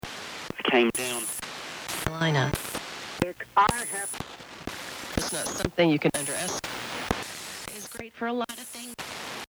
The Spirit Box swept through the otherworldly static in the atmosphere for an answer, and what we heard was quite intriguing:
Here's an mp3 of the Spirit Box audio: